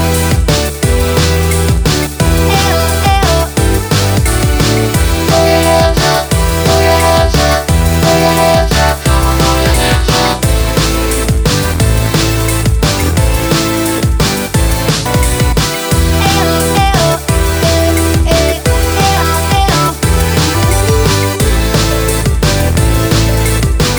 no Backing Vocals or voc Dance 2:58 Buy £1.50